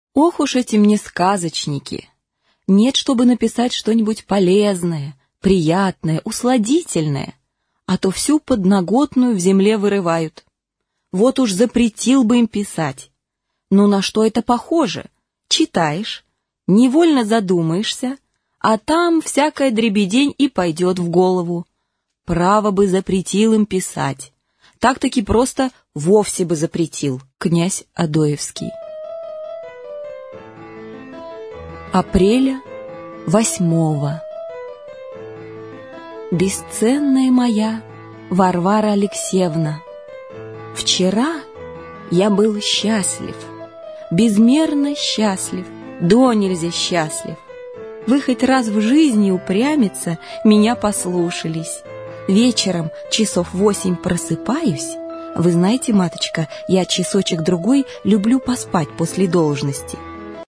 Аудиокнига Белые ночи. Бедные люди | Библиотека аудиокниг